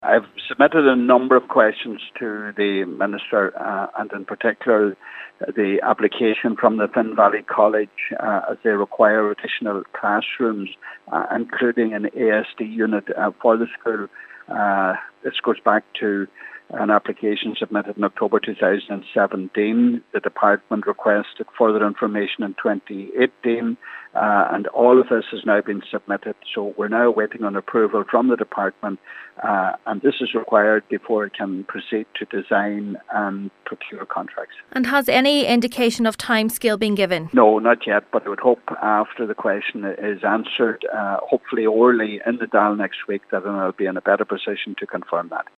Donegal Deputy Pat the Cope Gallagher is urging Minister Joe McHugh to expedite the approval: